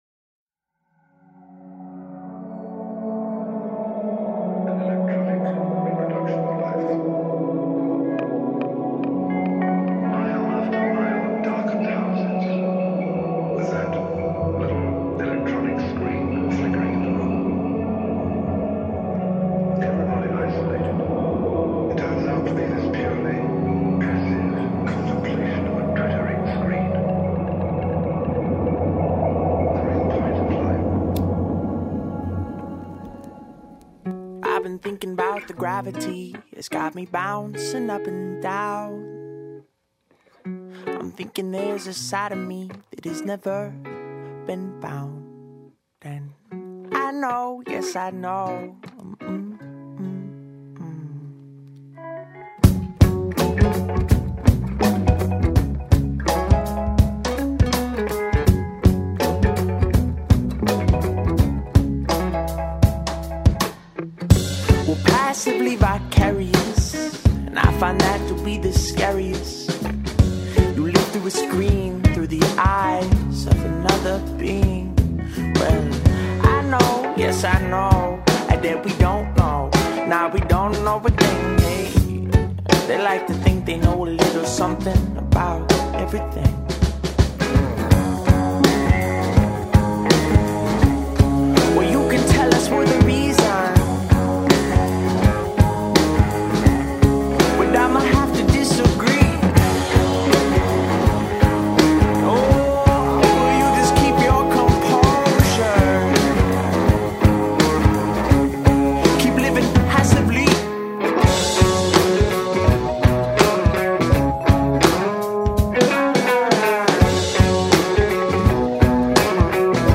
indie beach rock band